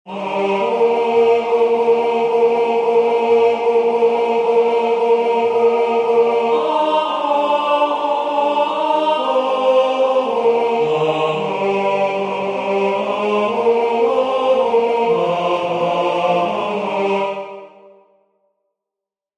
I+heard+the+Bells+on+Christmas+Day+Tenor.mp3